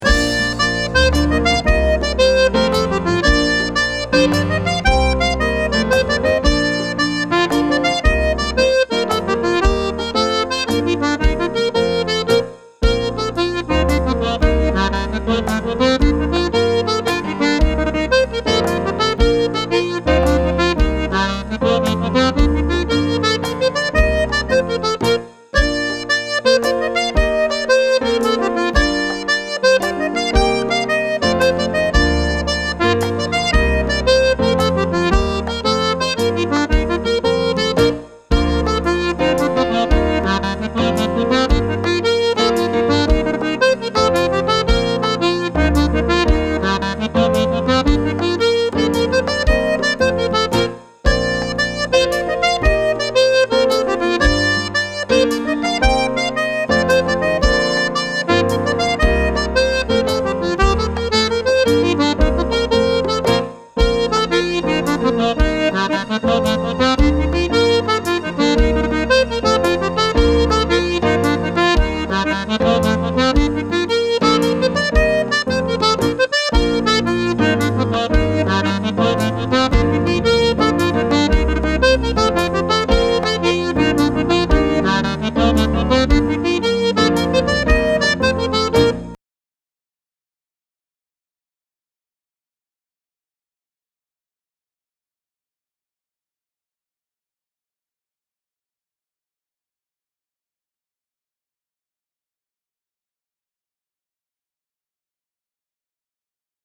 Slip Jigs